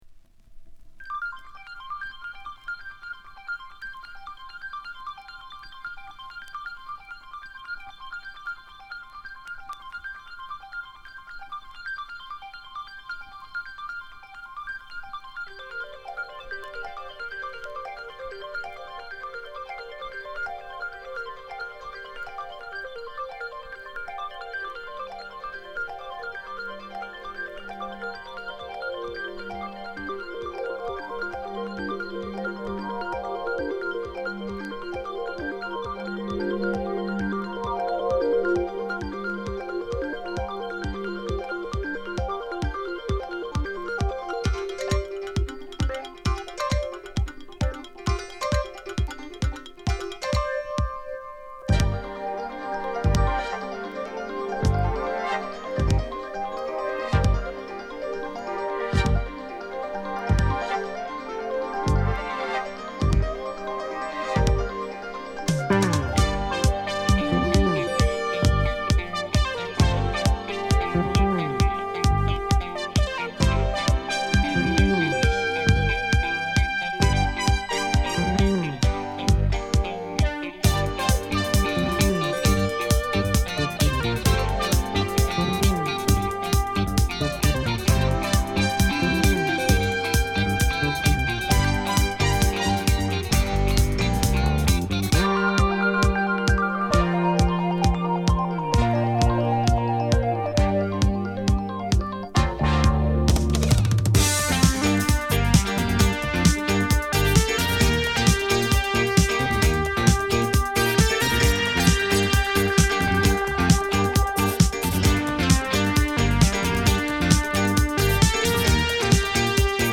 エレクトリックなディスコブレイクもカッコイイ